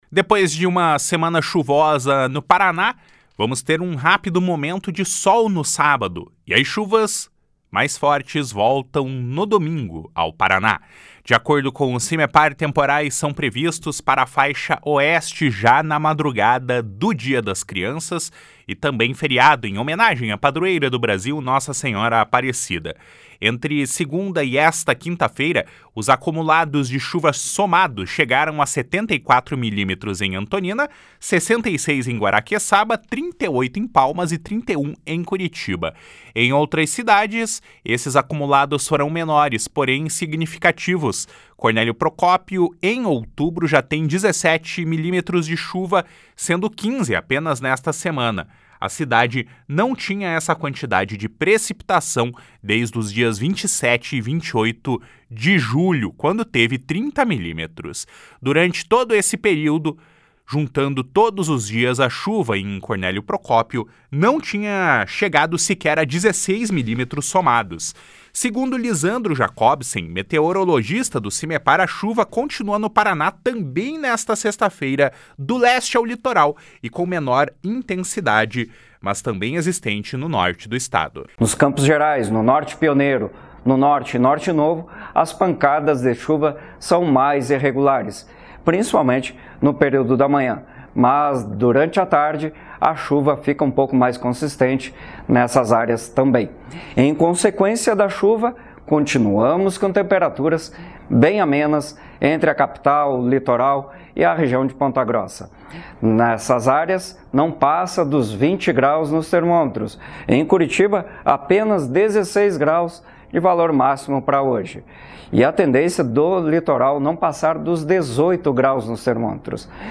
Ainda segundo o meteorologista, no domingo as condições do tempo mudam e se agravam principalmente na faixa Oeste do Paraná.